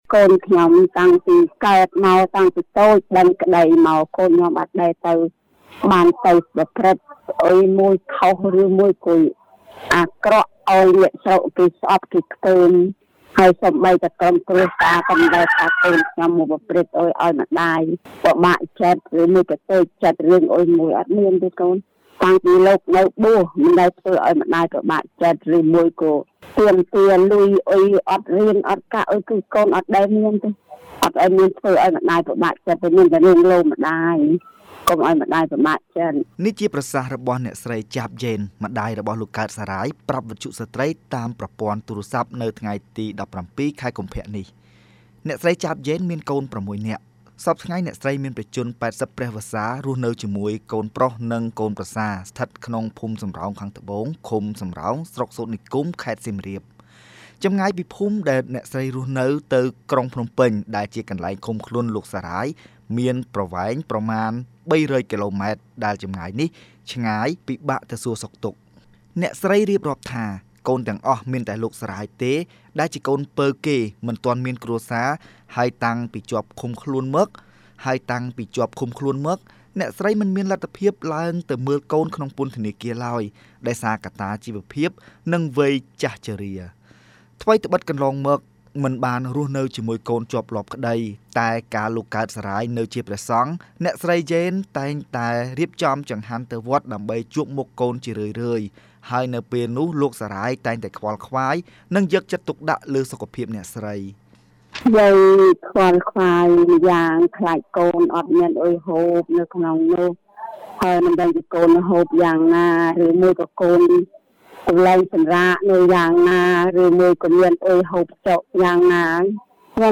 ប្រាប់វិទ្យុស្ដ្រីតាមប្រព័ន្ធទូរស័ព្ទនៅថ្ងៃទី១៧ ខែកុម្ភៈនេះ។